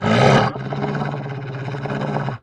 Soundscape Overhaul / gamedata / sounds / monsters / dog / bdog_groan_3.ogg
bdog_groan_3.ogg